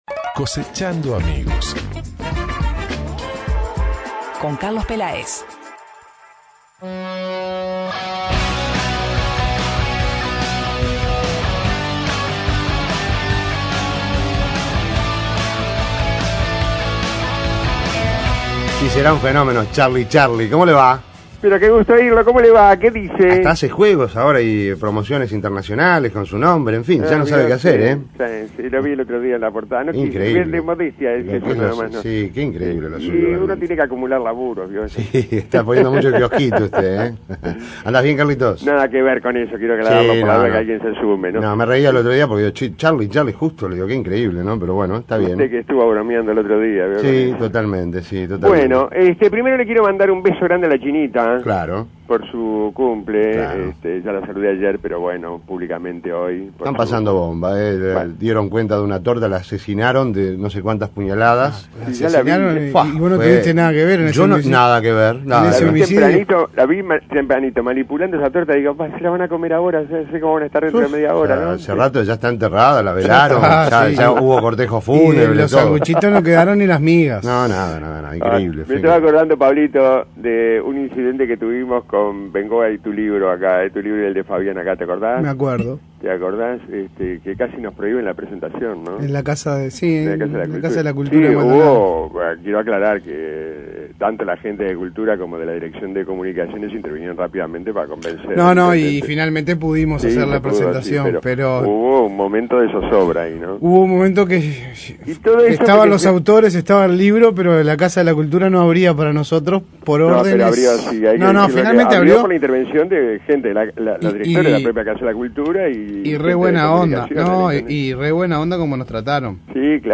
Descargar Audio no soportado Discurso pronunciado en 1971 en la ciudad de Mercedes